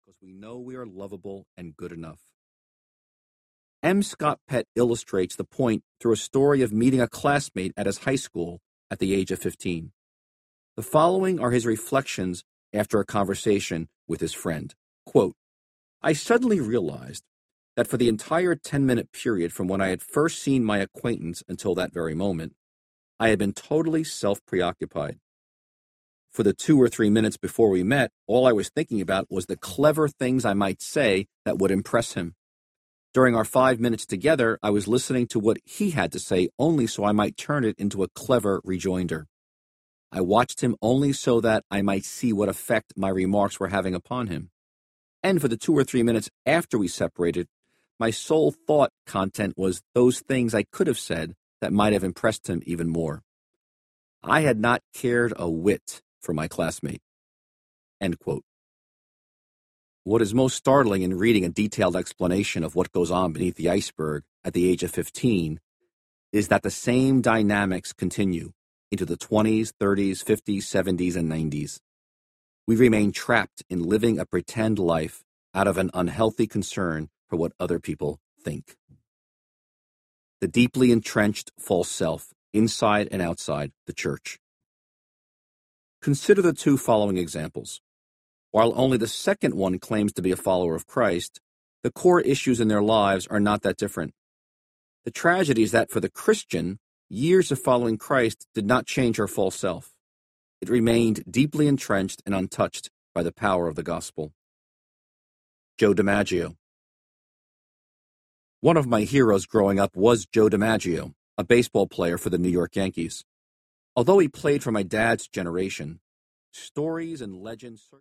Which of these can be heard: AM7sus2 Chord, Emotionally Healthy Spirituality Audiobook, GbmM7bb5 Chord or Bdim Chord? Emotionally Healthy Spirituality Audiobook